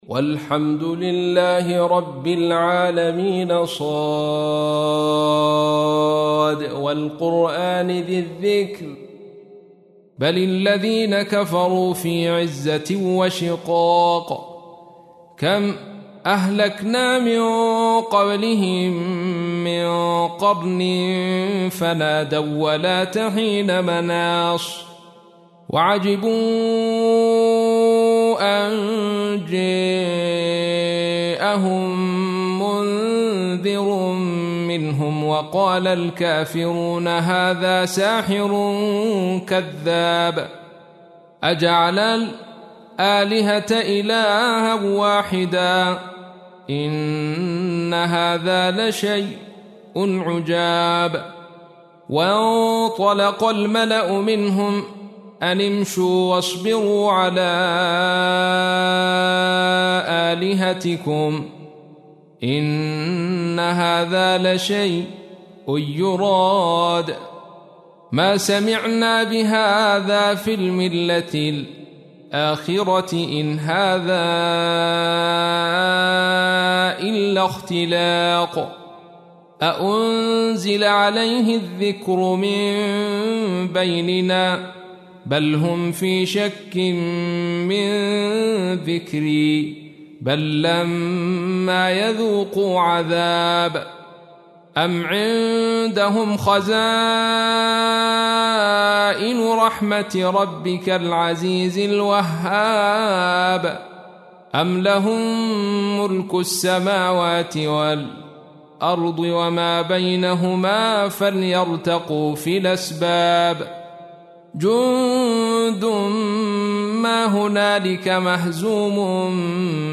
تحميل : 38. سورة ص / القارئ عبد الرشيد صوفي / القرآن الكريم / موقع يا حسين